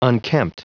Prononciation du mot unkempt en anglais (fichier audio)
Prononciation du mot : unkempt